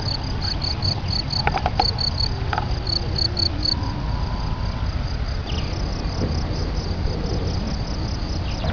Abb. 01: Gezirpe auf einer Wiese.
Beim Reiben der beiden Flügel aneinander entsteht ein schrilles Geräusch, es dient zum Anlocken von Weibchen oder Abgrenzen des Reviers (FB)